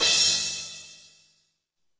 电子碰撞（11）立体声
描述：数字撞镲与立体声效果
Tag: 碰撞 鼓数字